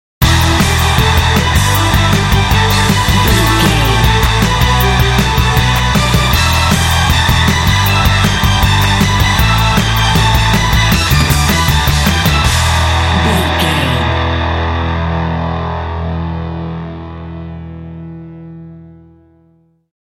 Aeolian/Minor
Fast
intense
powerful
energetic
bass guitar
electric guitar
drums
strings
heavy metal
symphonic rock